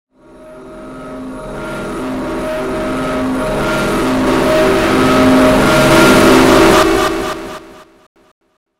Sound Effects
Loud Screaming